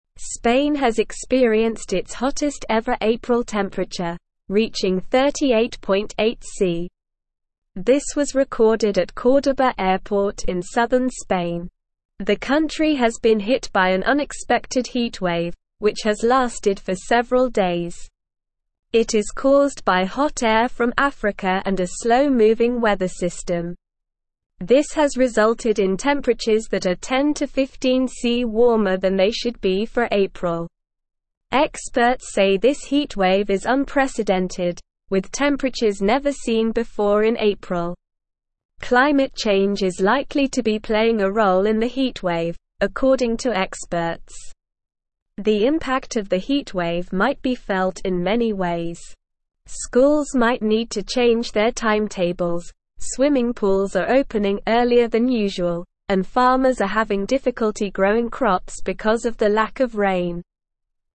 Slow
English-Newsroom-Beginner-SLOW-Reading-Spain-Has-Hottest-April-Temperature-Ever.mp3